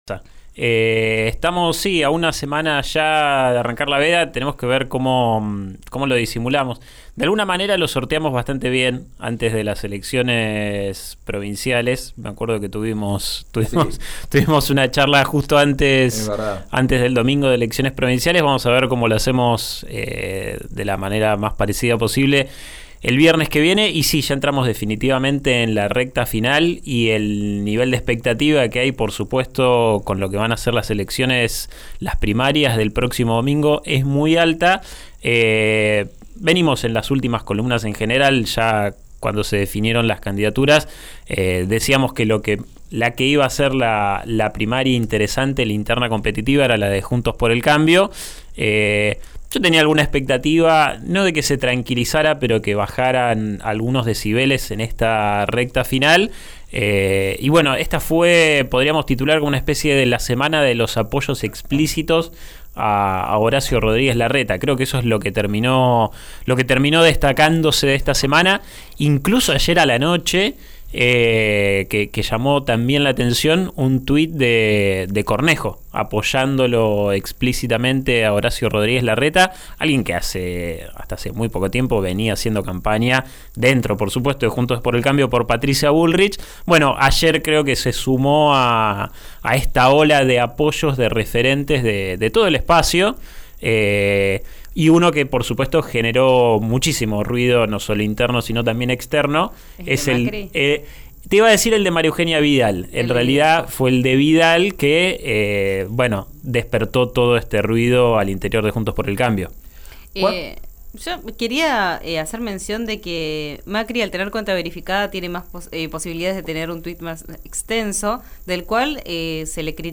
Nueva columna en RÍO NEGRO RADIO